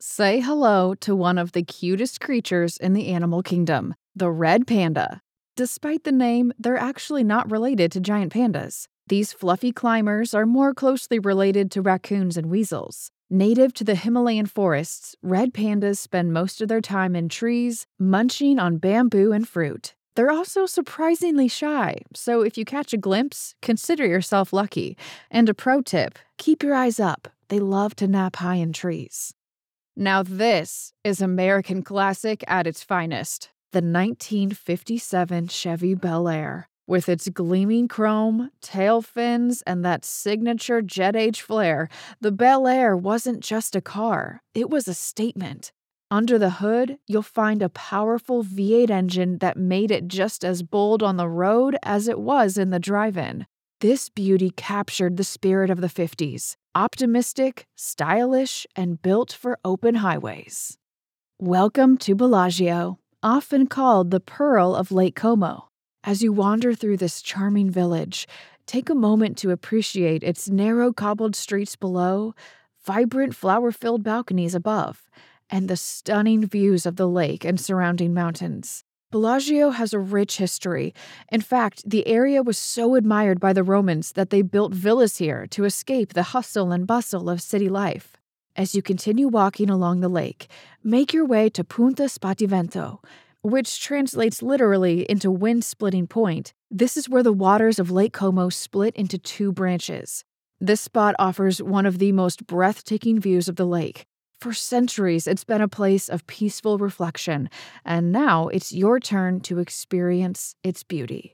Engels (Amerikaans)
Natuurlijk, Stedelijk, Warm
Audiogids